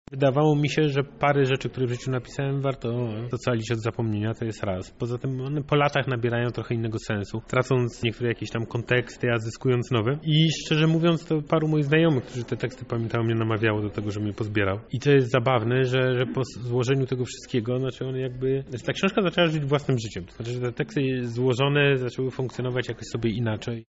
Podczas spotkania, odpowiadał On na pytania publiczności oraz promował swoją książkę „Między Wariatami”.